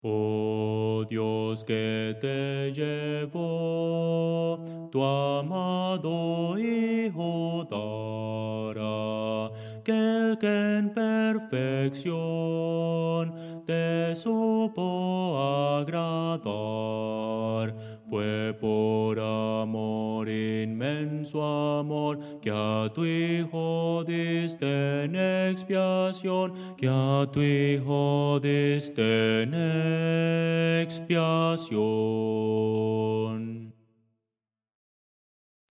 Voces para coro